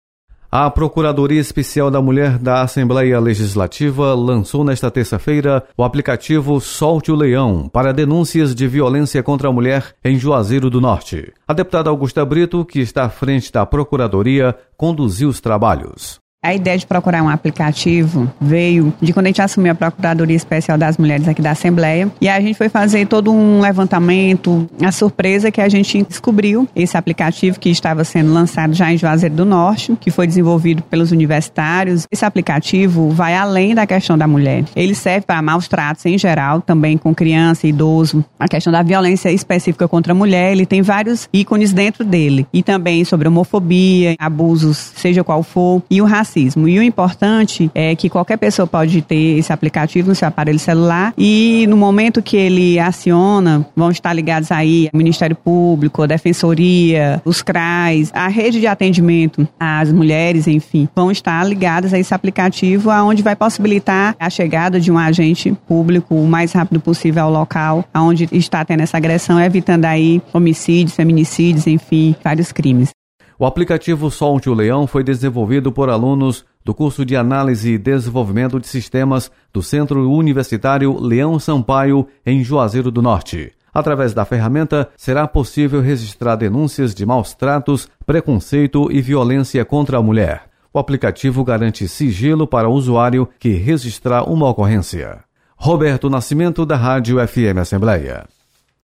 Você está aqui: Início Comunicação Rádio FM Assembleia Notícias Aplicativo